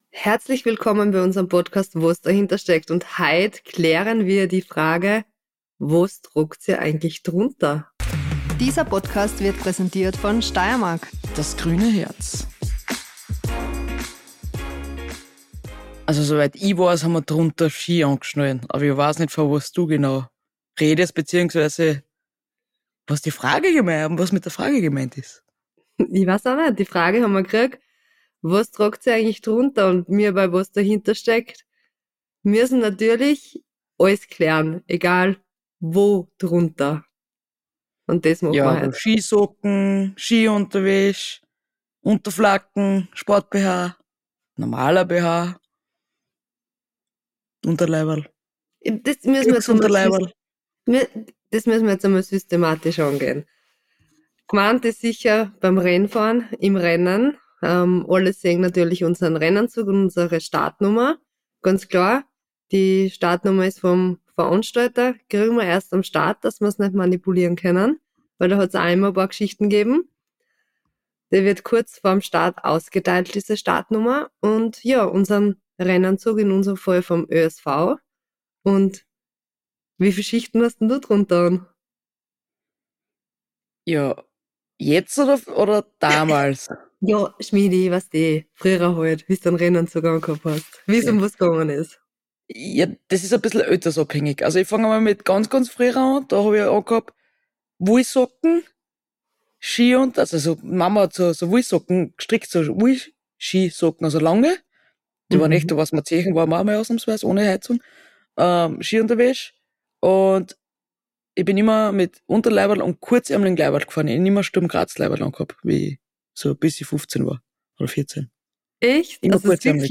Jede Minute wird genützt - „wos dahinter steckt“ direkt vom Flughafen – Wos dahinter steckt – Lyssna här